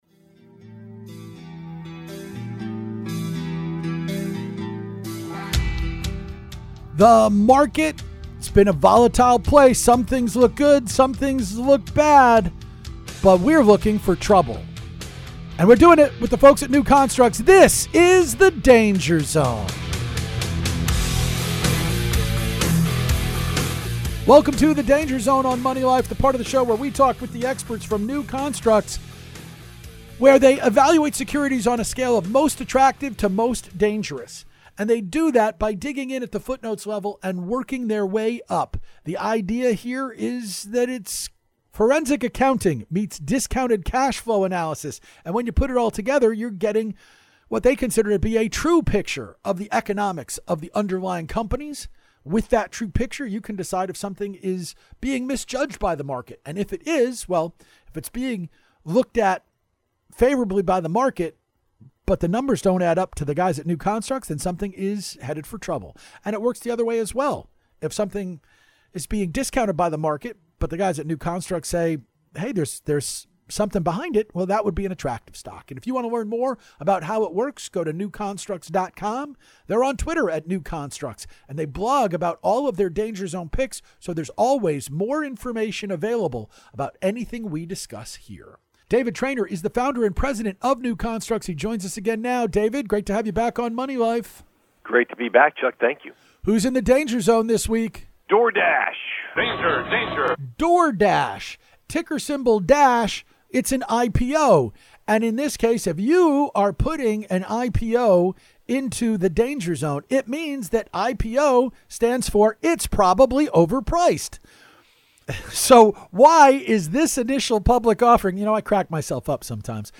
Danger Zone interview